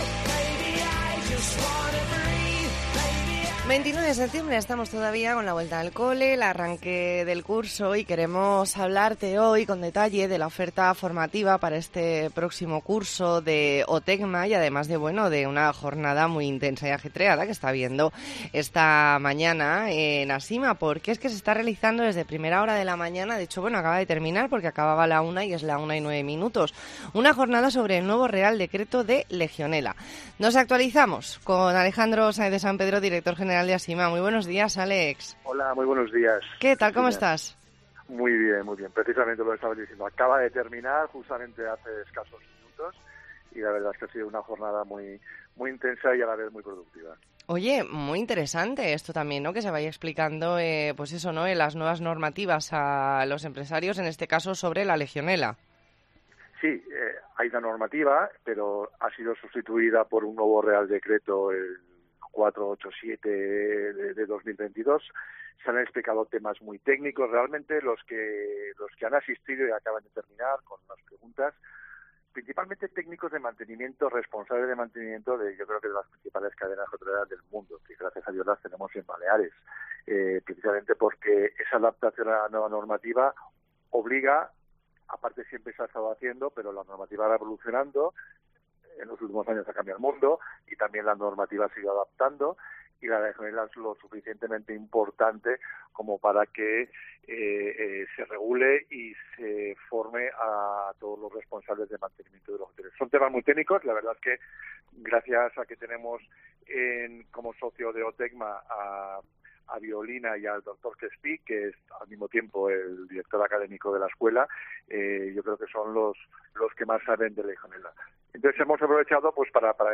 ntrevista en La Mañana en COPE Más Mallorca, jueves 29 de septiembre de 2022.